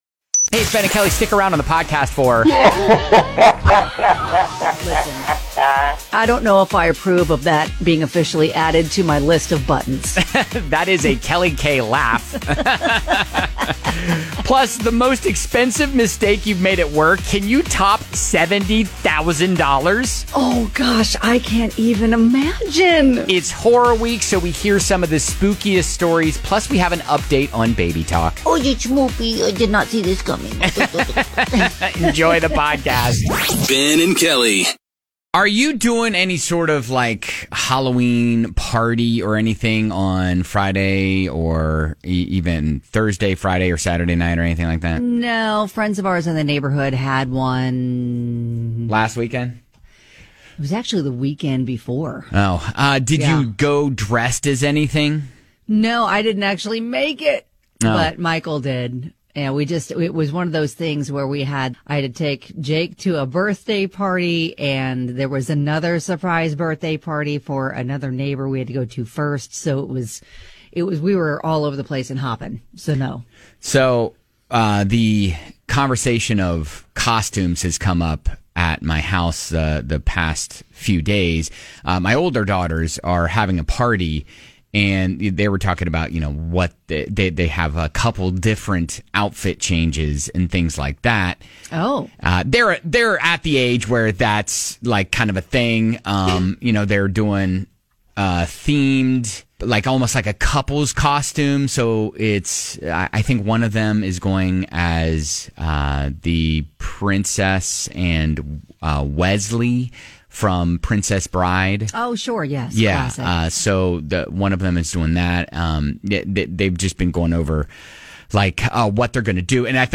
Plus evil laughing...or little kids?